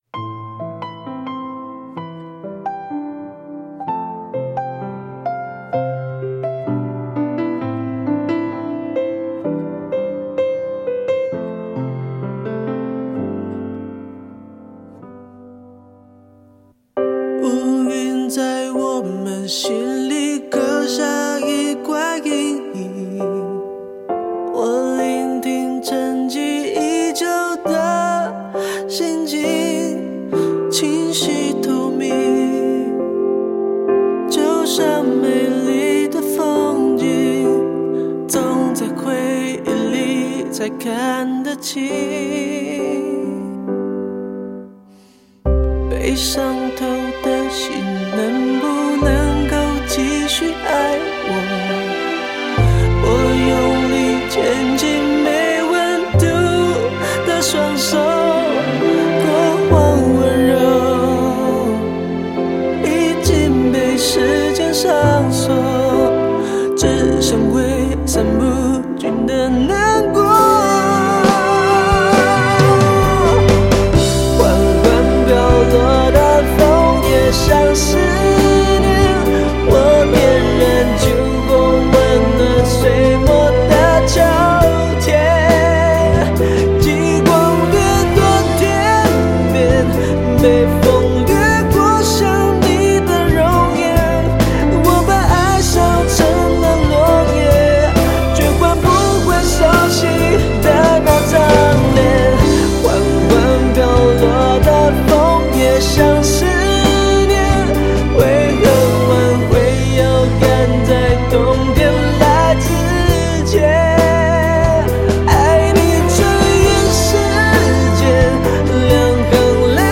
呈现出惆怅萧瑟的气味